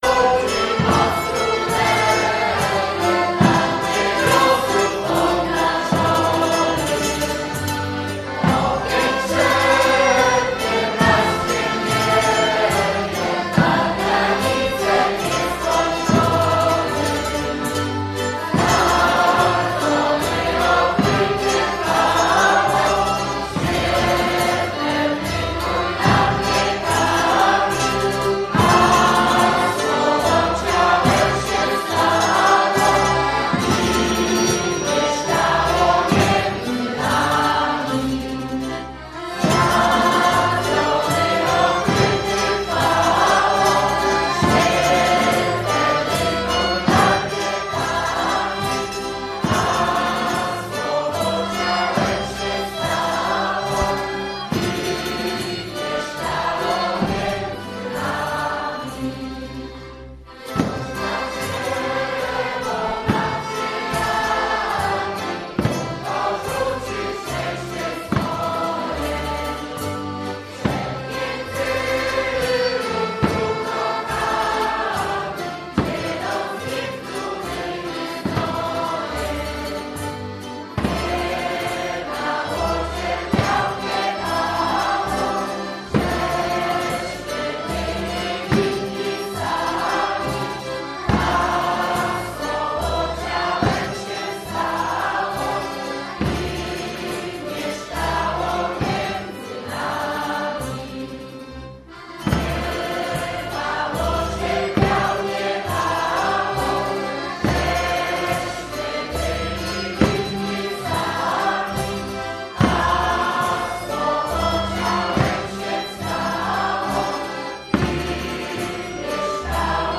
Wigilia Chłopska czyli spotkanie zespołów ludowych z całego regionu odbyło się w sobotę (16.12) w Suwałkach. Były życzenia, dzielenie się opłatkiem i kolędowanie.